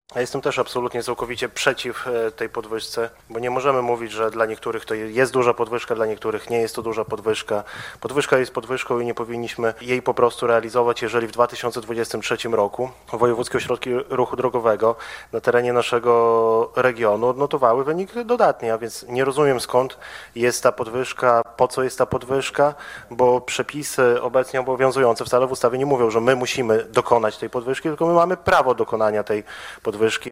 Radni Prawa i Sprawiedliwości podczas sesji wyrażali swój sprzeciw, podkreślając, że decyzja jest nieuzasadniona: